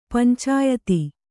♪ pancāyati